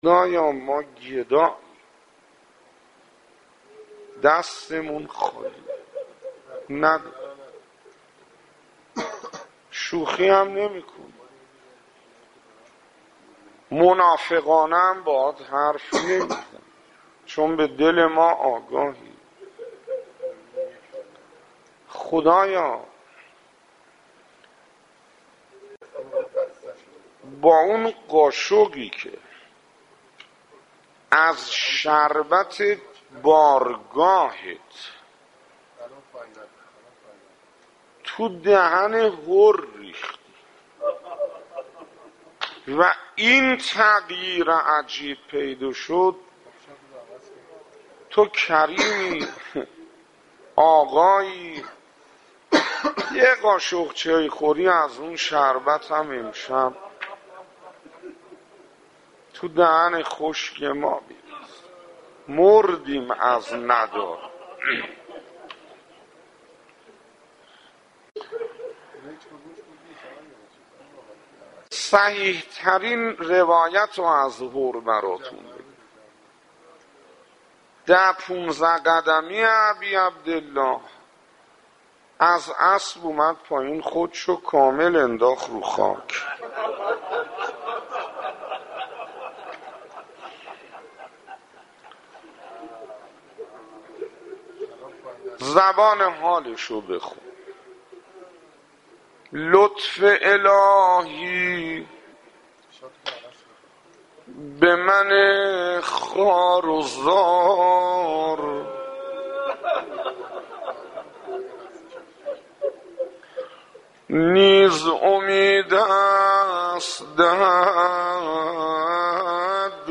روضه حر - 2
روضه حر - 2 خطیب: استاد حسين انصاريان مدت زمان: 00:05:28